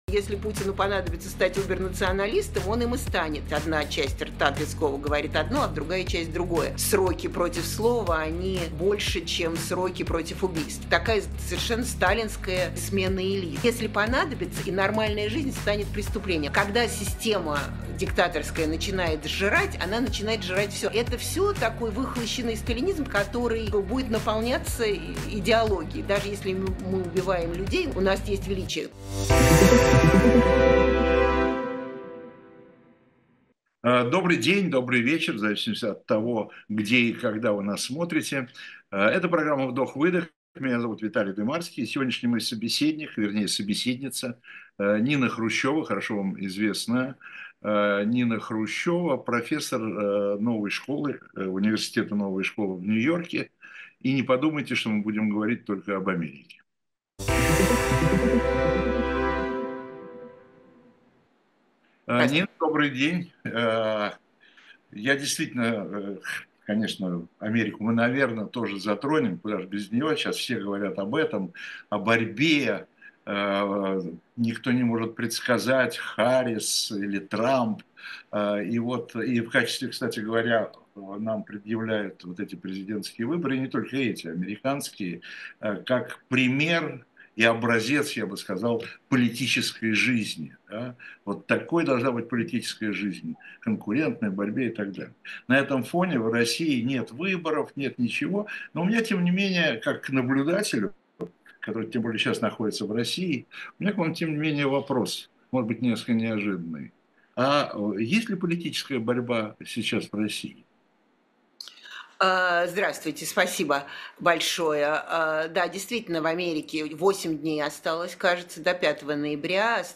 Программу ведет Виталий Дымарский.